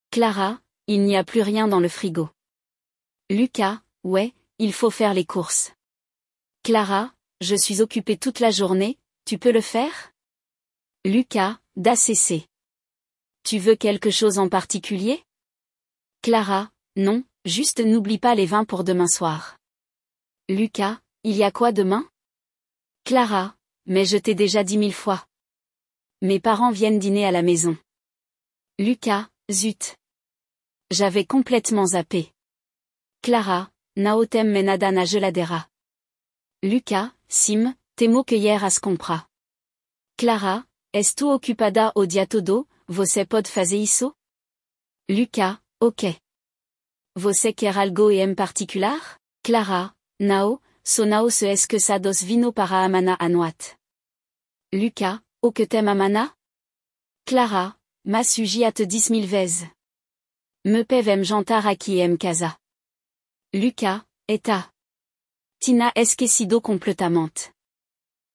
Aprenda a falar sobre as compras do mercado em francês a partir desta conversa entre um casal, em que eles precisam decidir quem vai fazer as compras para um jantar especial!